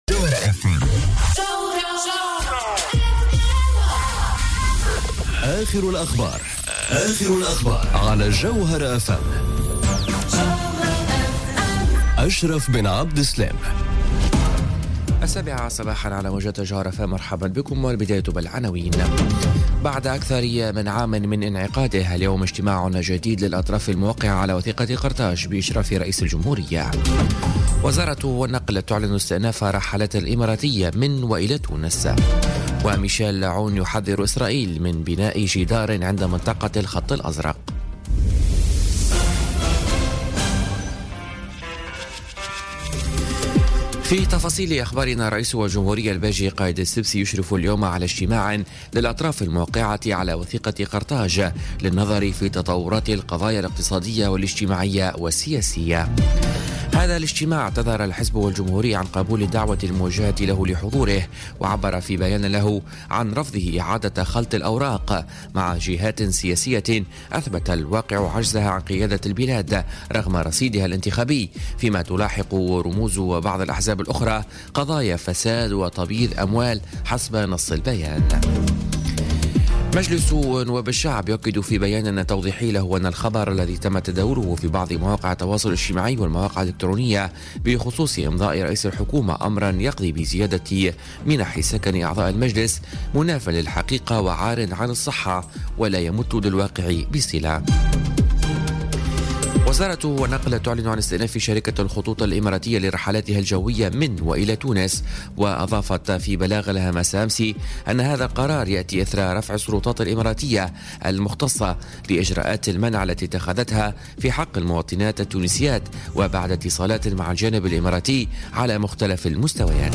نشرة أخبار السابعة صباحا ليوم الجمعة 5 جانفي 2018